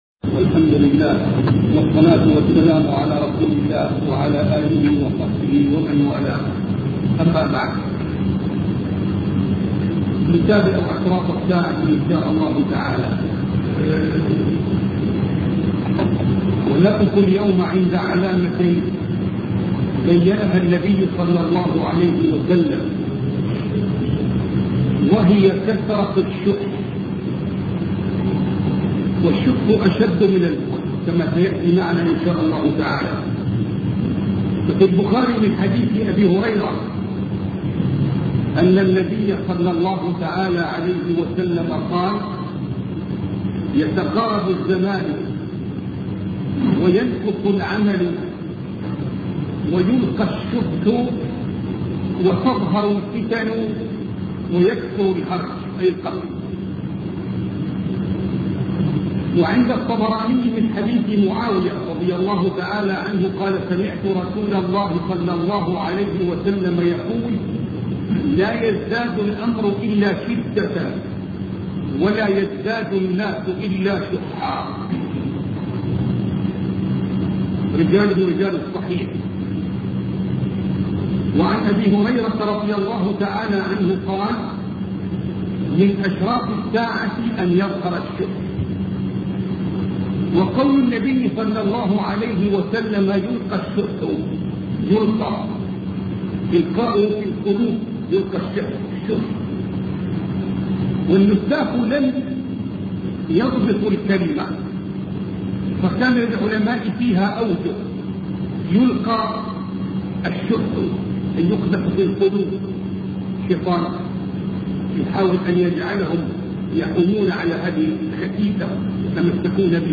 سلسلة محاضرات أشراط الساعة الوسطئ